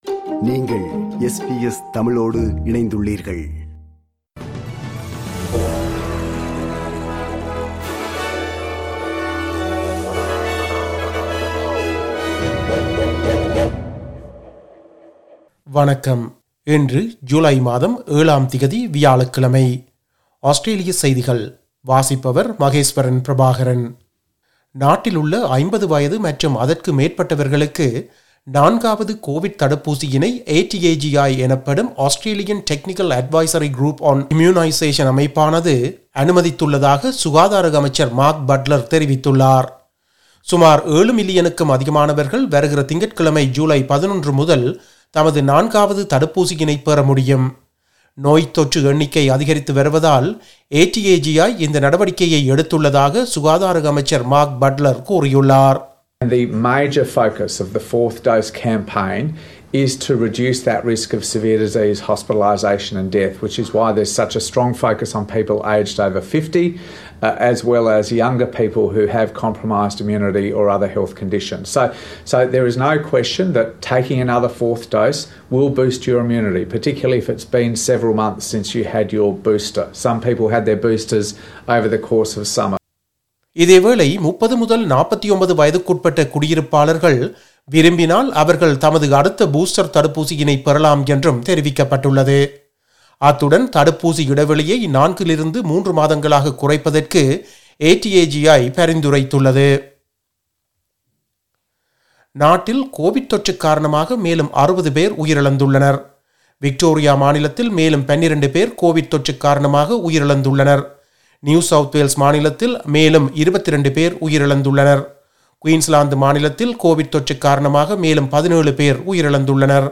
Australian news bulletin for Thursday 07 July 2022.